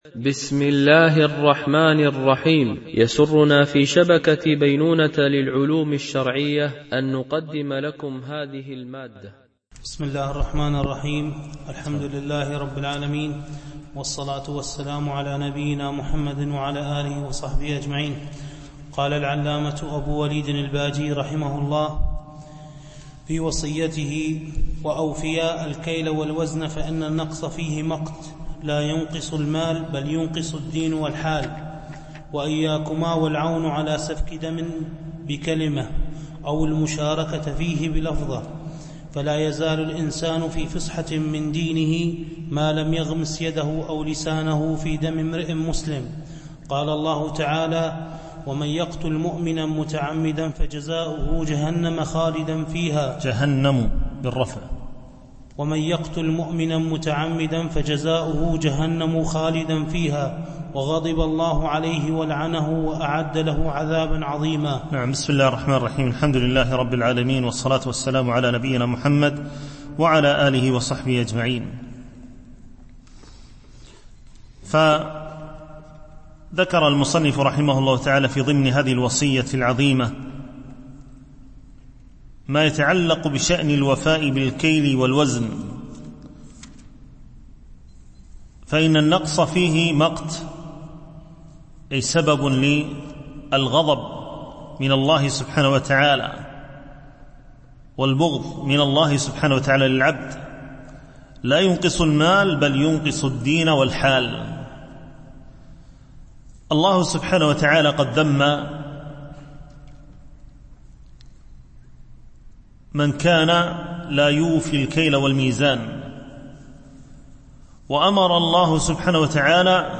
شرح النصيحة الولدية ـ الدرس 11